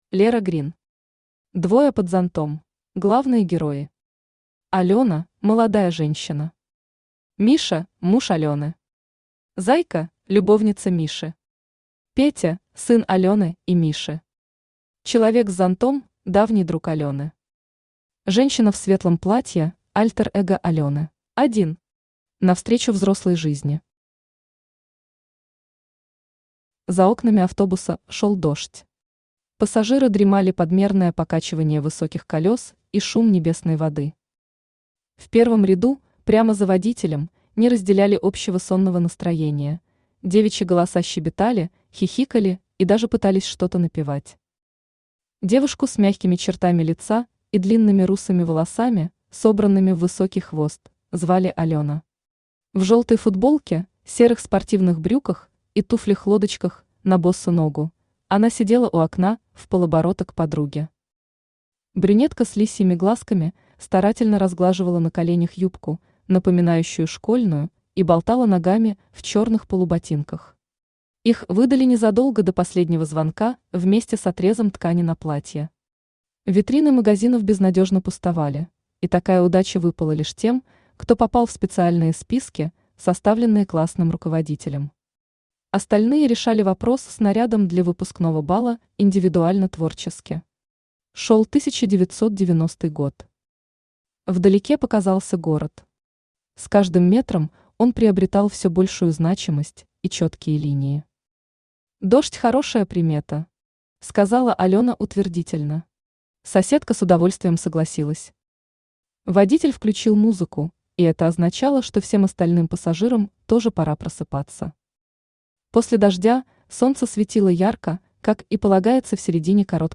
Аудиокнига Двое под зонтом | Библиотека аудиокниг
Aудиокнига Двое под зонтом Автор Лера Грин Читает аудиокнигу Авточтец ЛитРес.